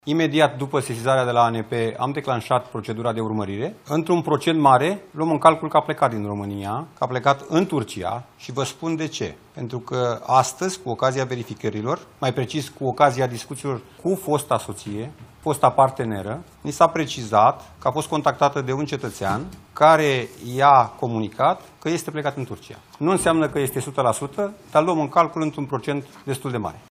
Secretarul de stat din Ministerul Afacerilor Interne, Bogdan Despescu: „Luăm în calcul că a plecat din România, că a plecat în Turcia”